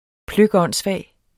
Udtale [ ˈpløgˈʌnˀˌsvæˀj ]